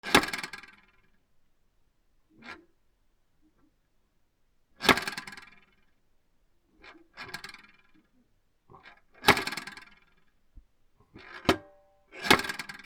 はかり キッチン